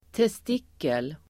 Ladda ner uttalet
testikel substantiv, testicle Uttal: [test'ik:el] Böjningar: testikeln, testiklar Synonymer: pungkula Definition: manlig könskörtel (i pungen) som producerar spermier testicle substantiv, testikel [anatomiskt]